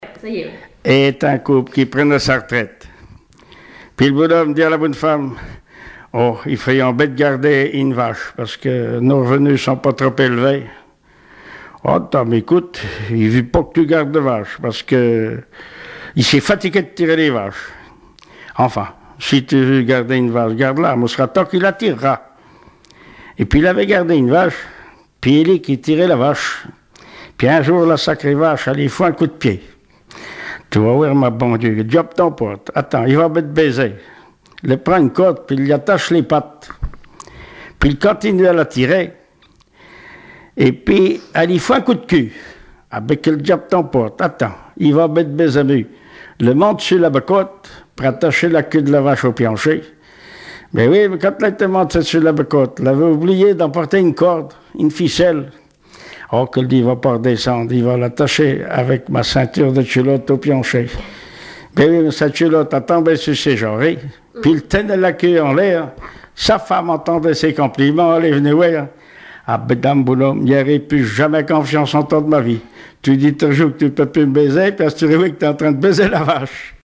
Patois local
Genre sketch
Catégorie Récit